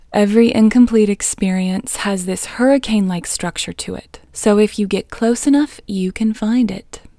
IN – the Second Way – English Female 7